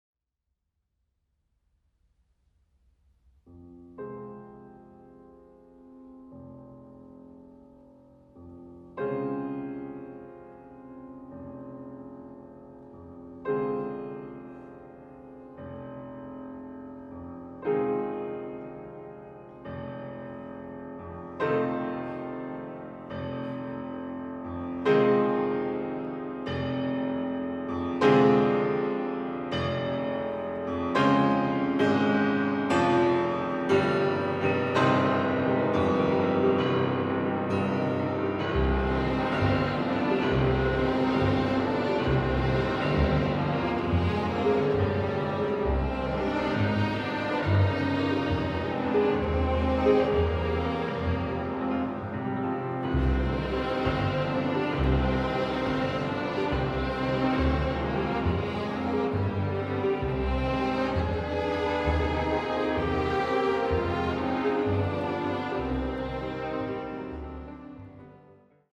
This evening’s celebration concert
piano concerto